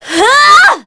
Scarlet-Vox_Casting1.wav